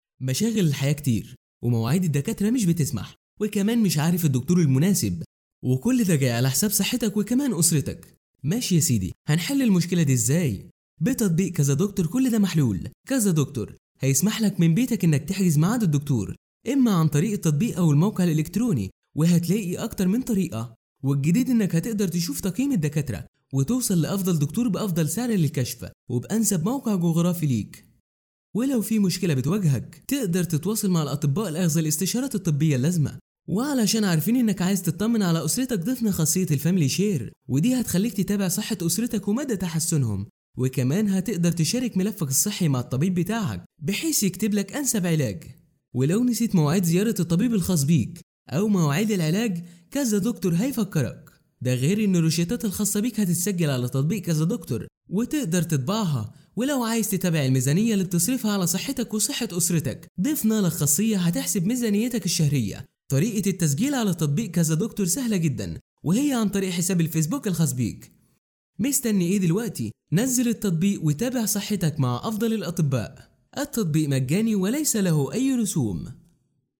Mısır Arapçası Seslendirme
Erkek Ses